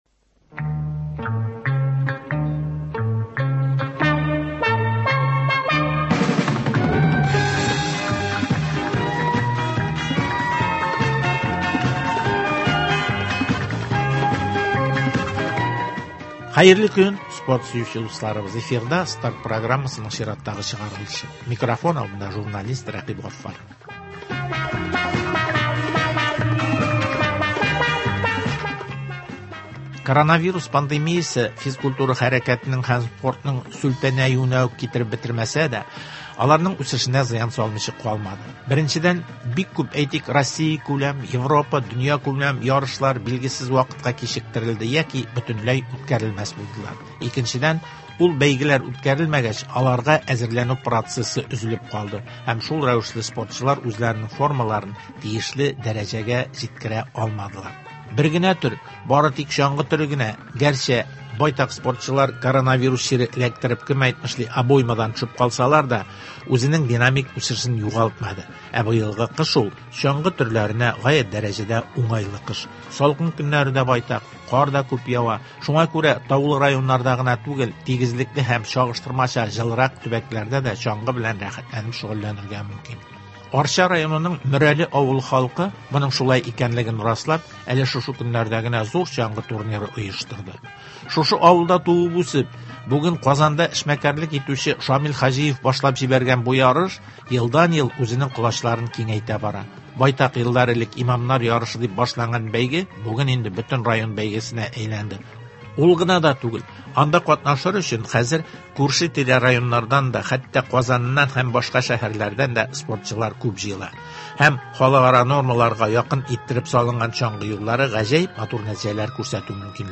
әңгәмә.